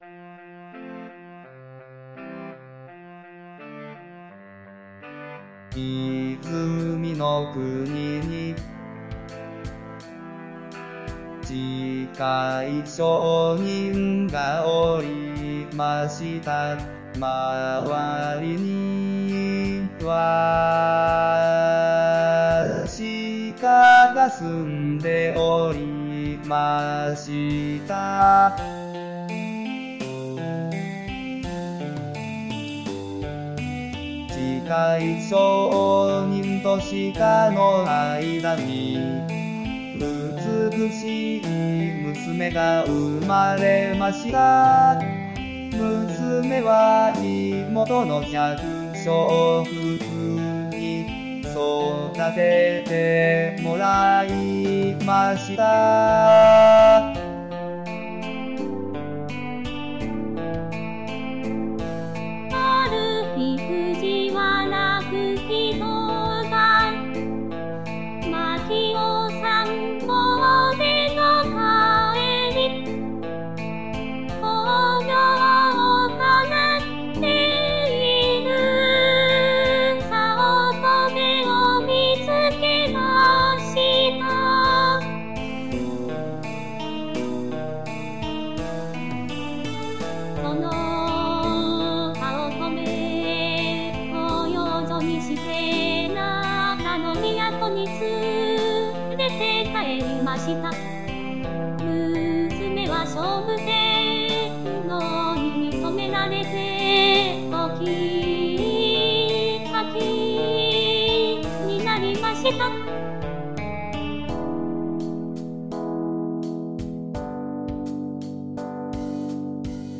日本語歌詞からAI作曲し、伴奏つき合成音声で最長10分歌います。